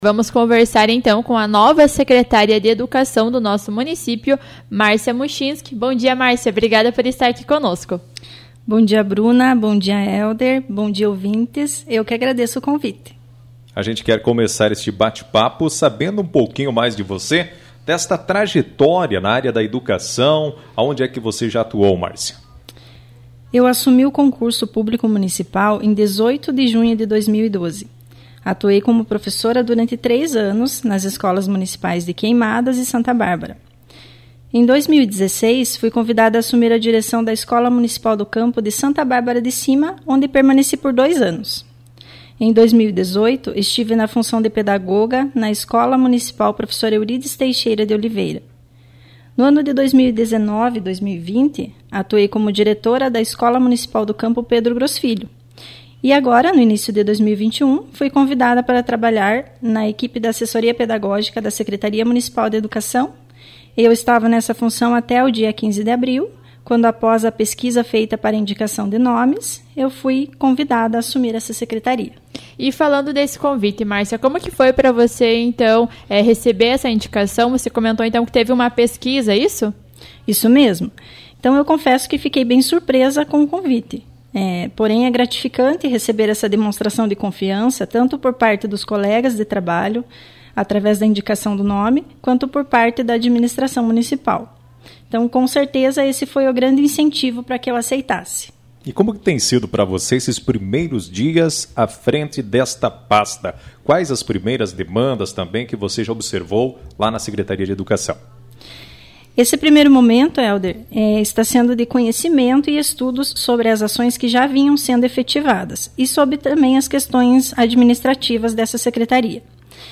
A Secretária Municipal de Educação Márcia Muchinski participou do Noticiário P7 desta terça-feira (27).